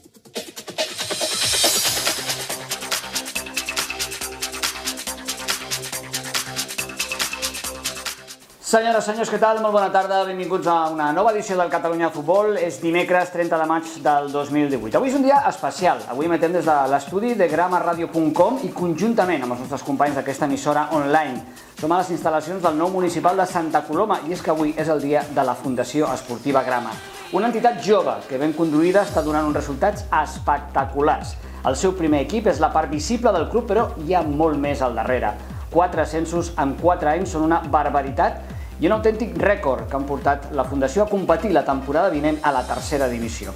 Presentació del programa de Ràdio Marca "Catalunya futbol" fet des dels nous estudis de Grama Ràdio en el dia de la seva inauguració
Esportiu